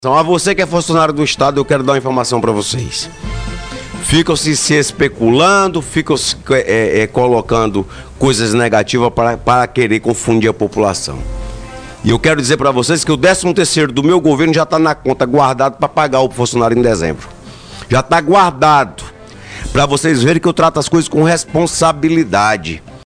Para “enterrar” de vez qualquer dúvida com relação ao pagamento em dia dos servidores públicos do estado na gestão Gladson Cameli, o próprio governador afirmou na manhã desta terça-feira, 21, na rádio Aldeia FM, que o 13° salário do funcionalismo público do Acre já está garantido.